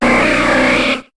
Cri de Lamantine dans Pokémon HOME.